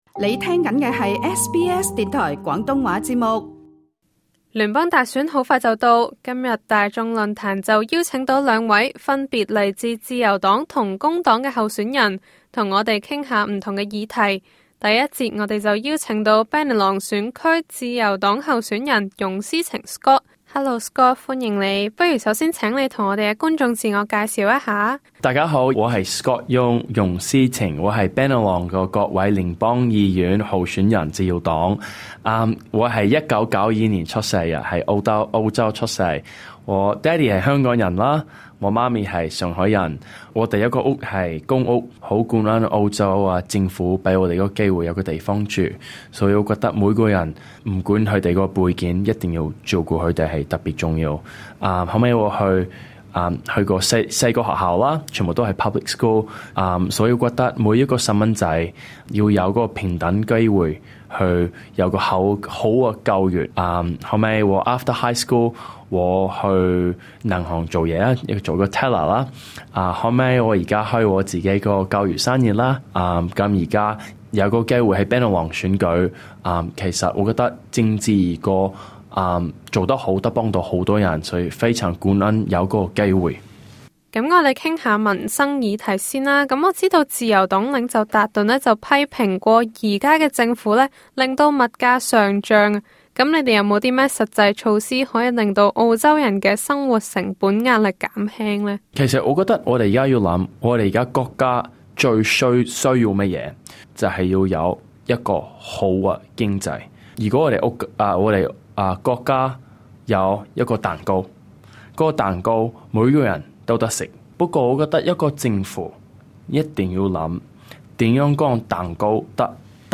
今集【大眾論壇】請來兩位華裔候選人